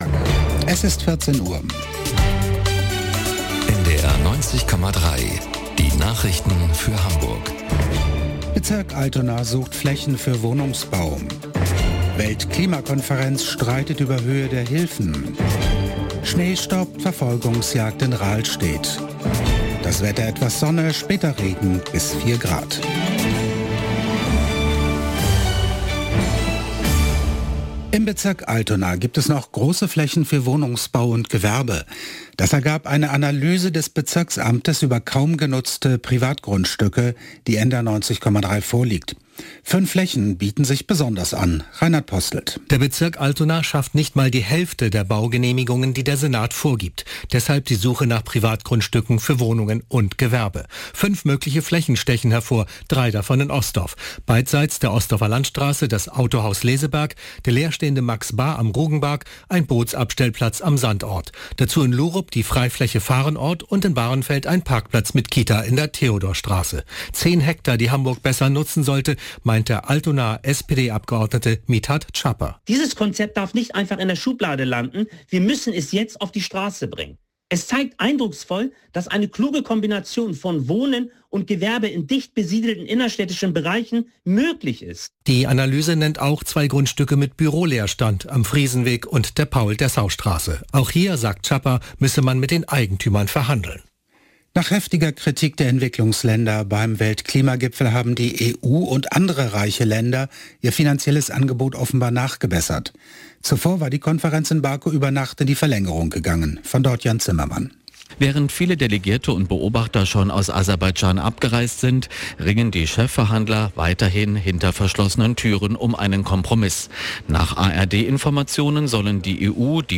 1 Nachrichten 4:07